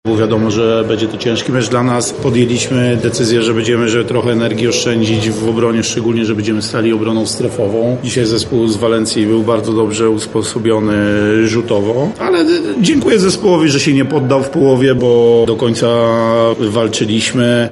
mówił na konferencji prasowej